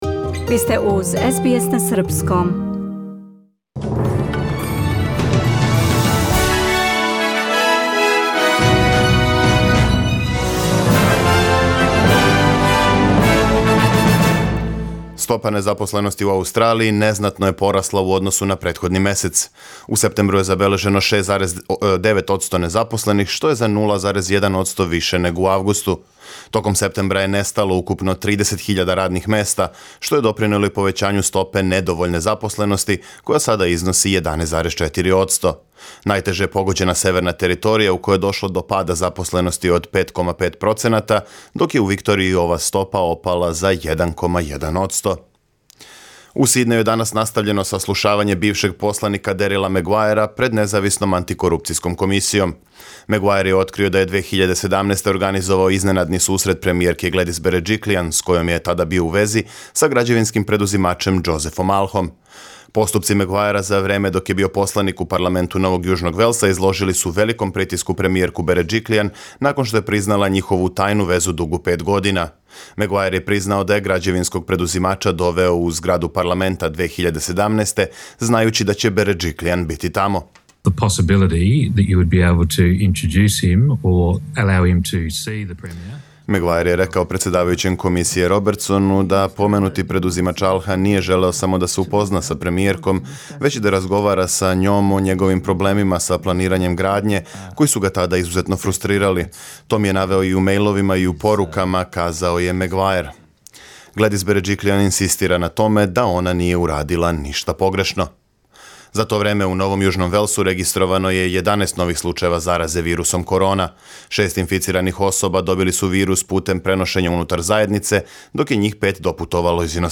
Преглед вести за 15. октобар 2020. године